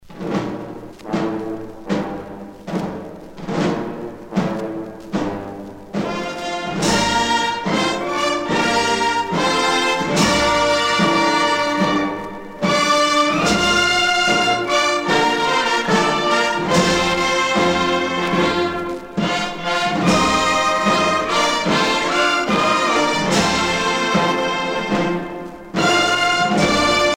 Fonction d'après l'analyste gestuel : à marcher
Usage d'après l'analyste circonstance : militaire
Pièce musicale éditée